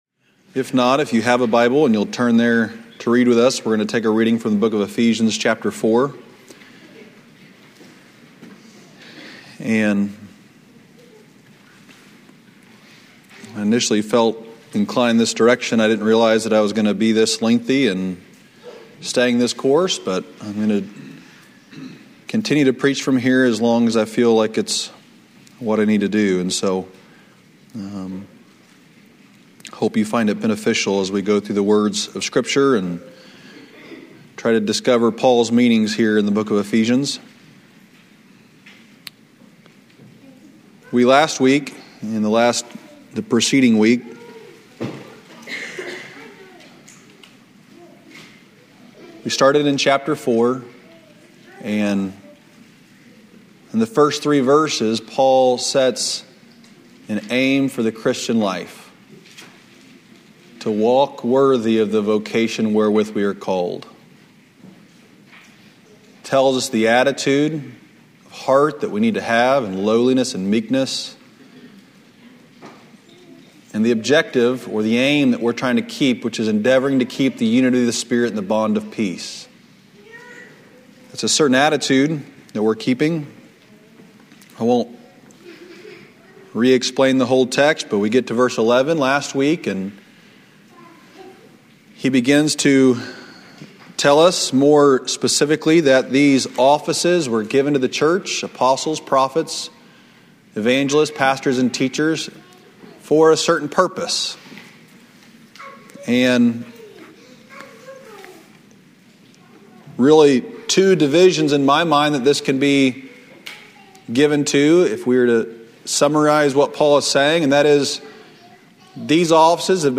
Sermons from our Sunday morning worship services.